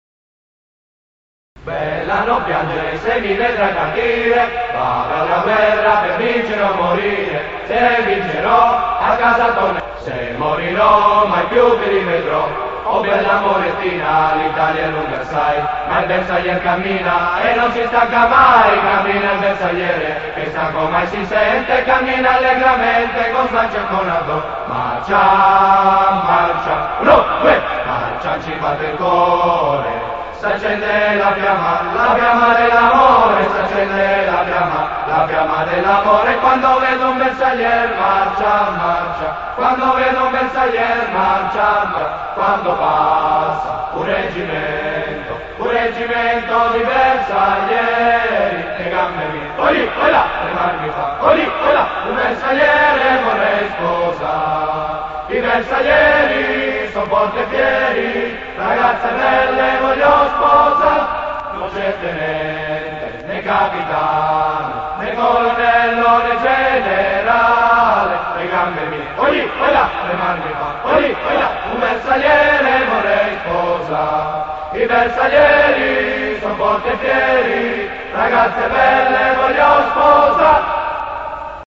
marcia d'ordinanza cantata.mp3